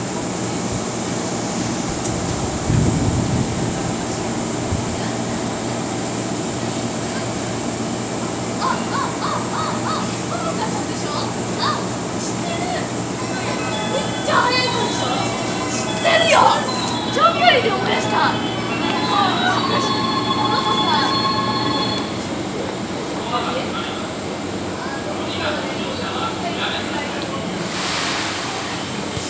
横浜線 中山駅　1番線 発車メロディー